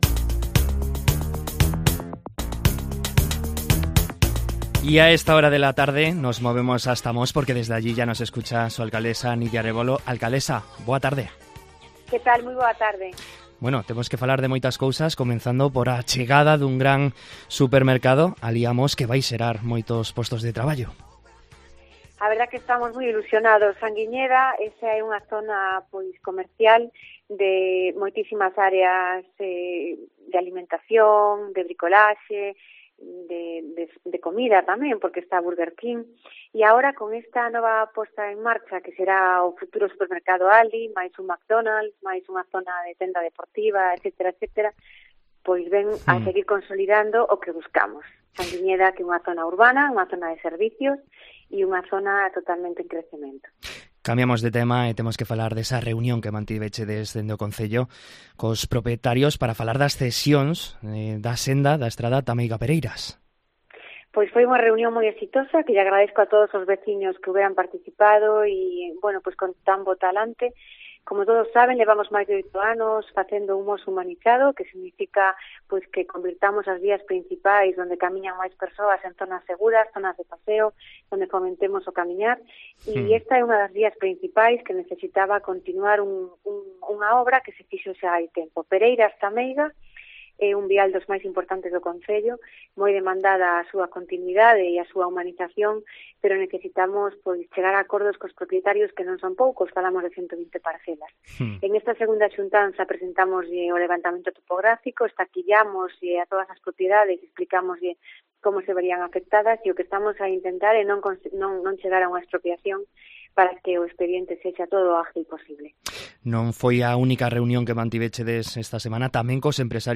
Coñecemos a actualidade de Mos da man da súa alcaldesa